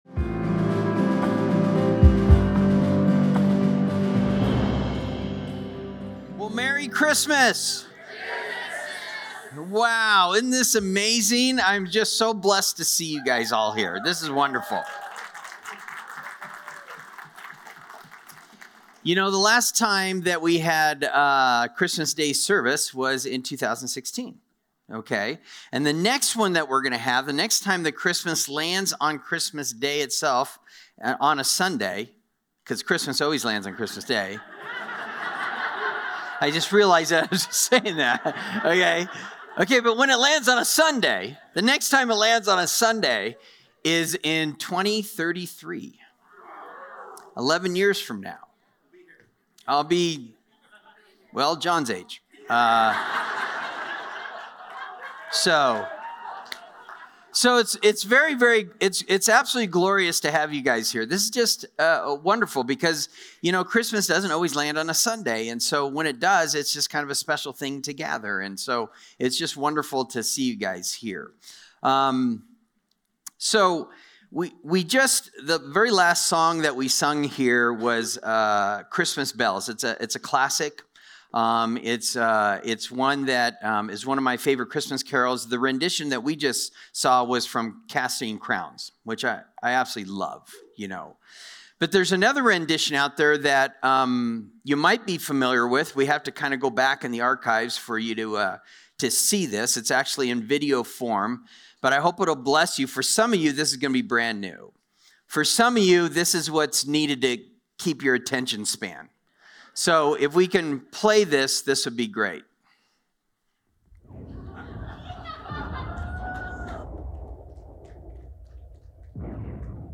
Christmas Morning Service 2022